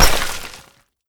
BulletImpact_Concrete02.wav